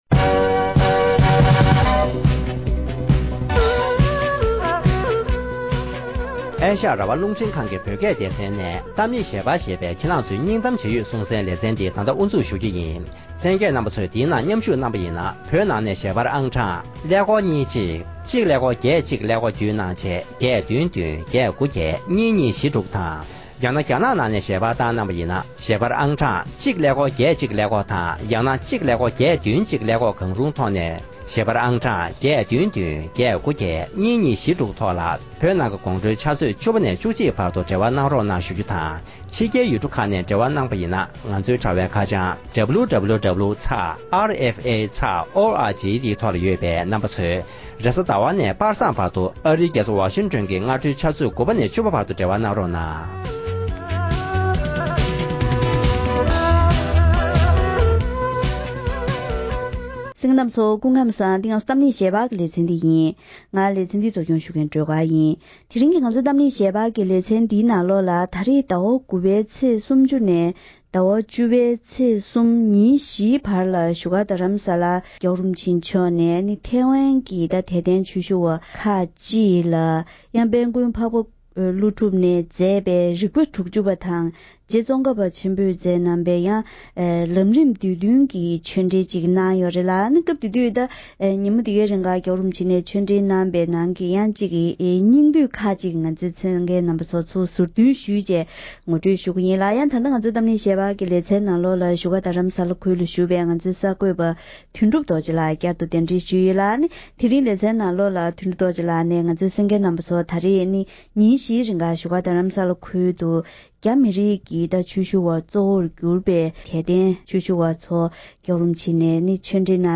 བཞུགས་སྒར་ལ་ཐེ་ཝན་གྱི་ཆོས་ཞུ་བར་ཆོས་འགྲེལ་གནང་བ།